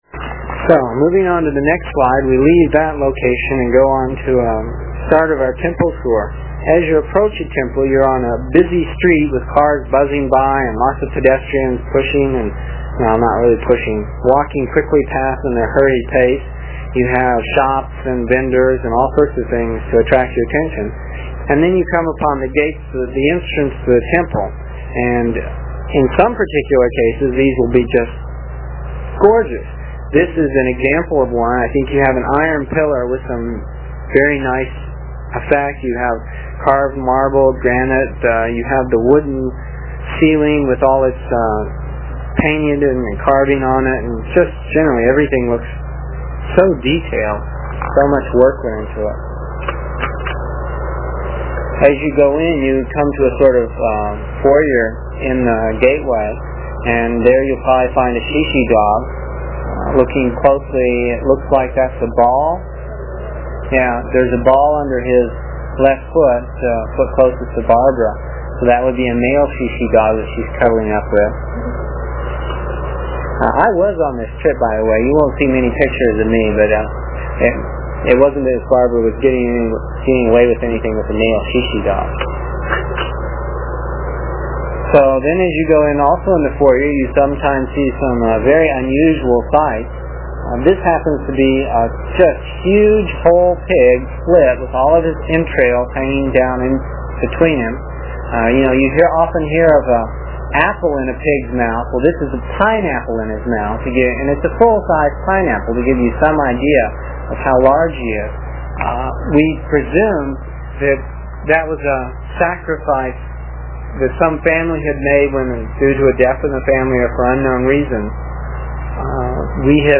It is from the cassette tapes we made almost thirty years ago. I was pretty long winded (no rehearsals or editting and tapes were cheap) and the section for this page is about seven minutes and will take about three minutes to download with a dial up connection.